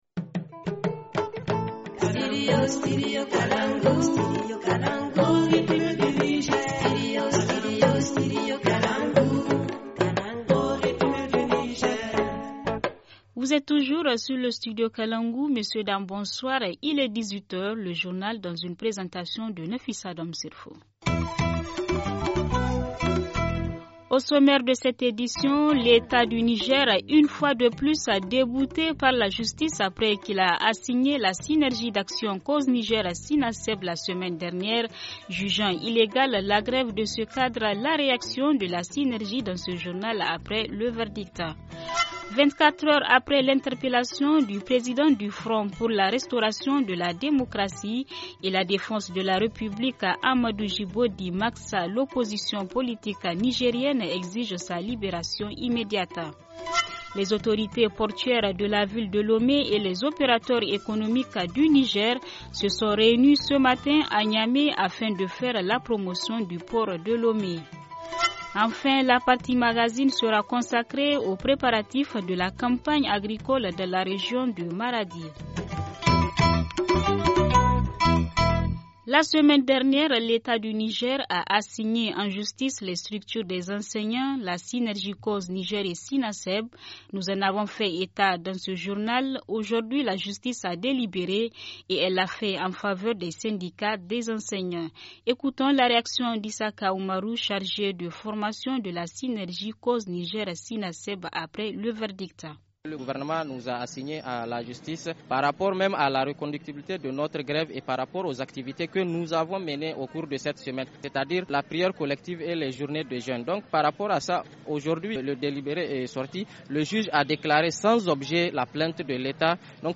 Journal du 16 mai 2017 - Studio Kalangou - Au rythme du Niger